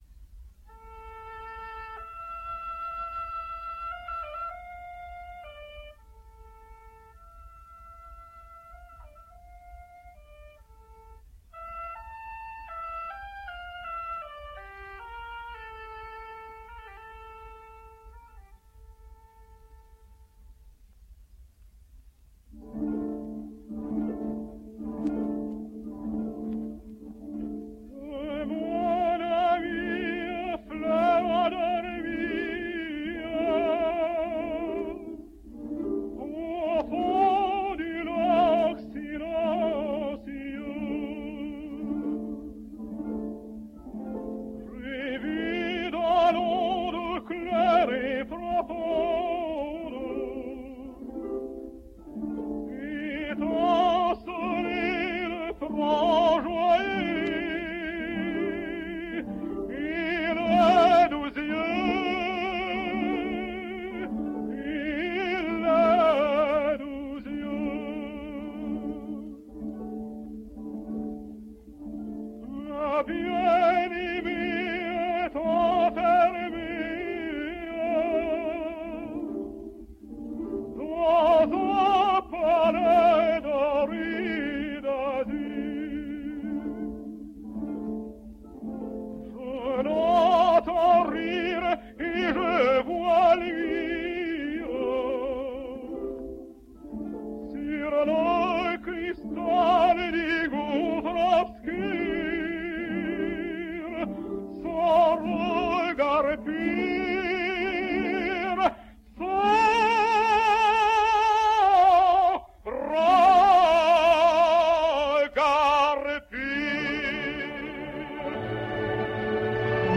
Russian Tenor.